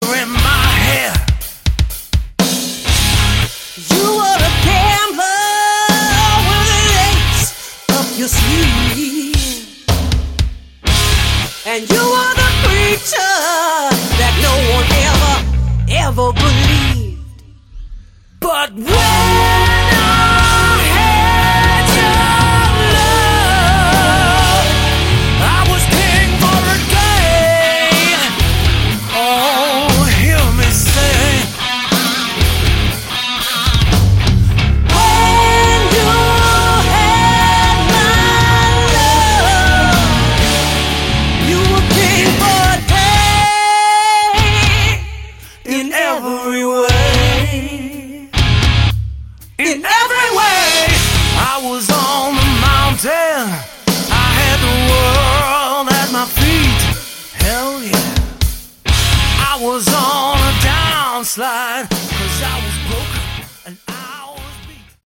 Category: Hard Rock
lead vocals
duet vocals